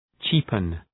cheapen.mp3